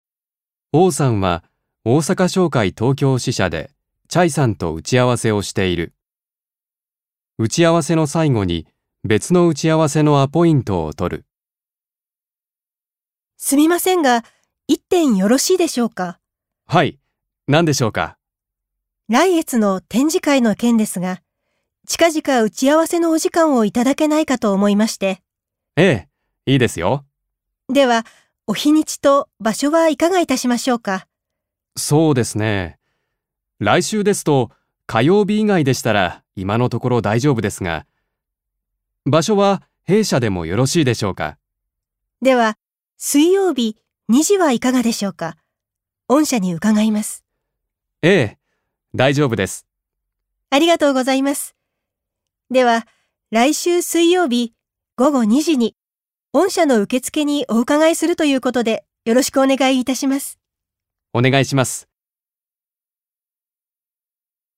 1. 会話
場面：王さんは、大阪商会東京支社おおさかしょうかいとうきょうししゃでチャイさんとわせをしている。